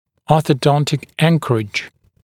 [ˌɔːθə’dɔntɪk ‘æŋkərɪʤ][ˌо:сэ’донтик ‘энкэридж]ортодонтическая опора, опора при проведении ортодонтического лечения